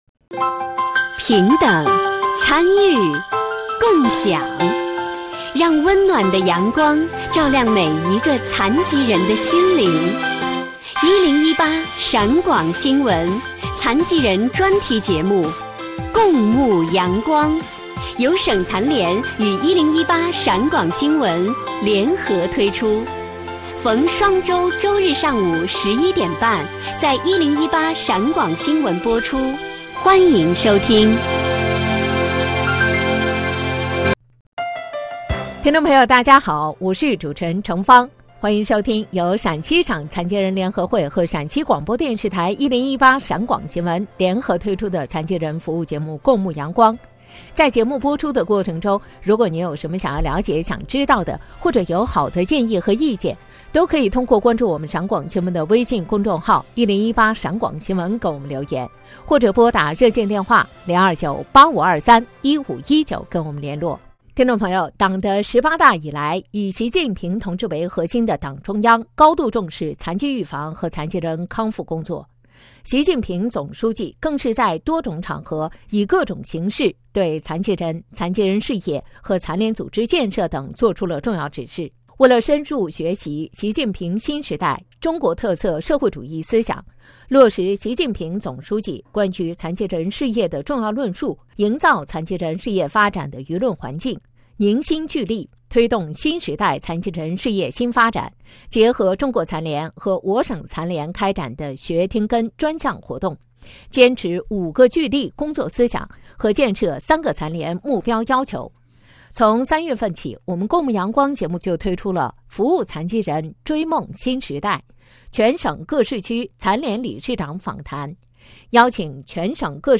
【理事长访谈】宝鸡残联理事长梁荣贤访谈
宝鸡残联理事长梁荣贤访谈.mp3